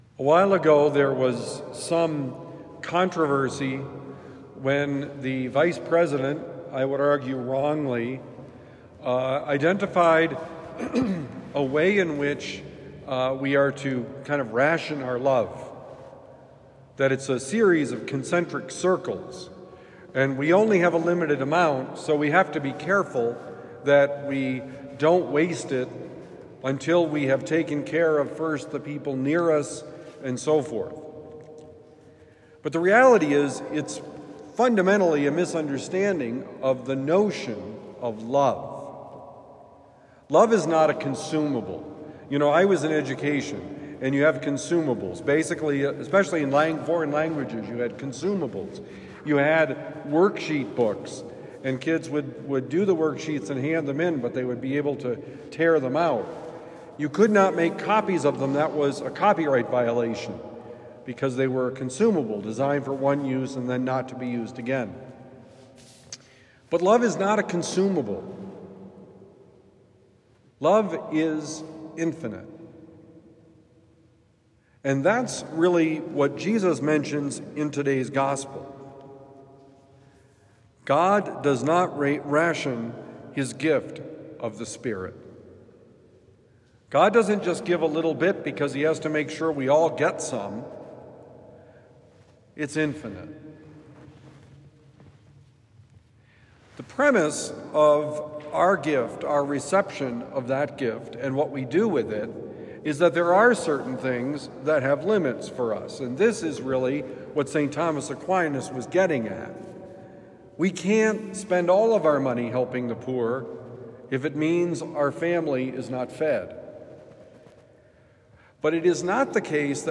No ration of the Spirit: Homily for Thursday, May 1, 2025